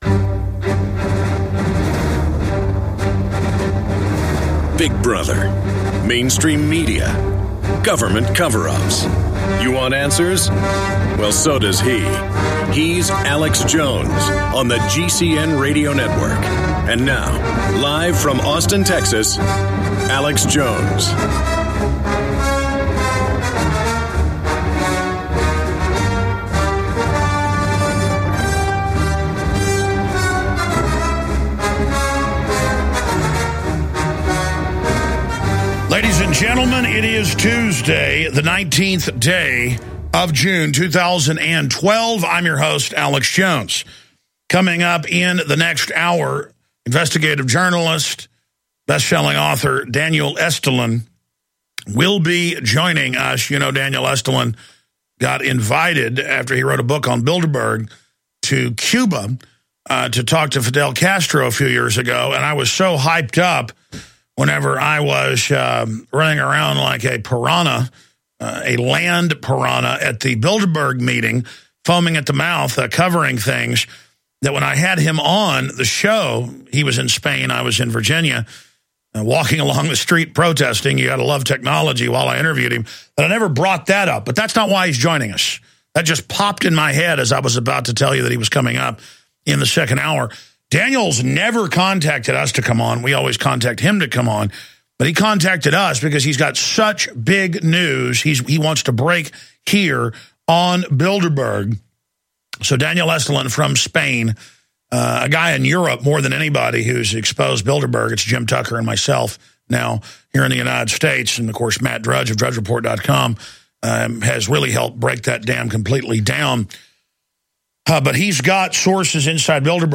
Alex Jones Show Commercial Free Podcast
Watch Alex's live TV/Radio broadcast.